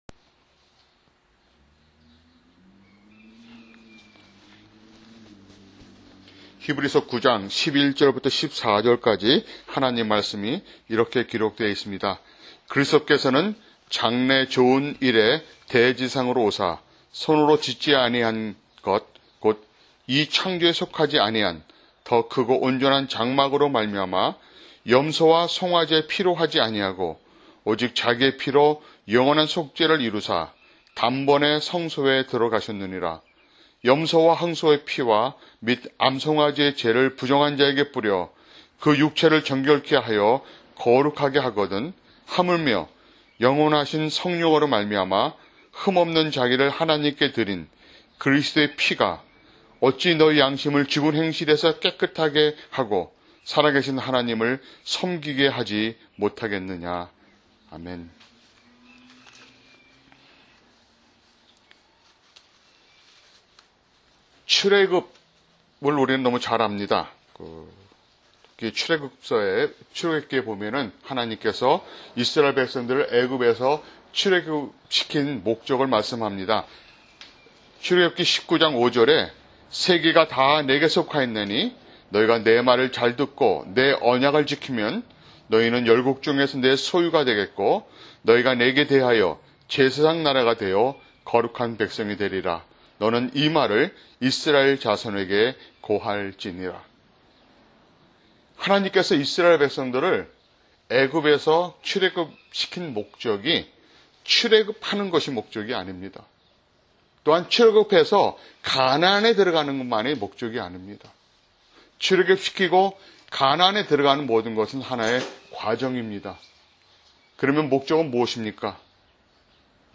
[주일설교] 히브리서 9:11-14
MP3 다운로드 (좌측 링크에 오른쪽 마우스 클릭 후 "다른 이름으로 저장") Labels: 주일설교 - 게스트 스피커 다음페이지 이전페이지 Home